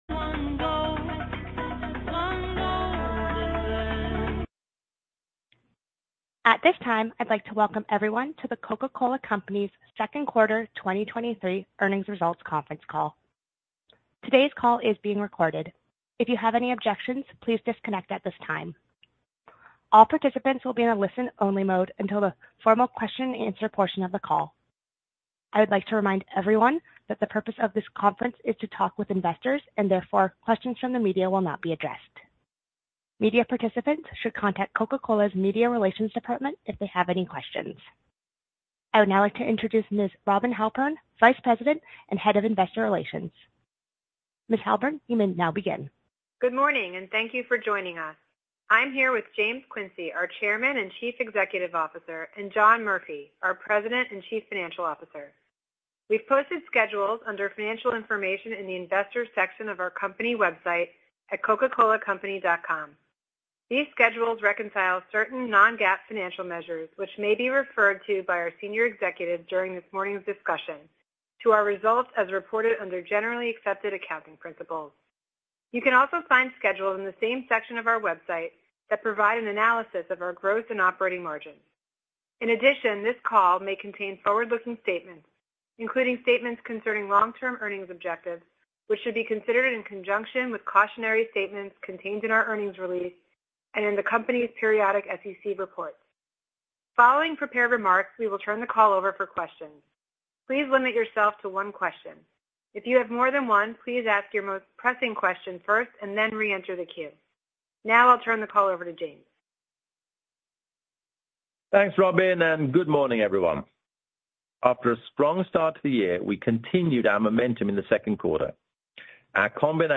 Earnings Call Q2 2023 Audio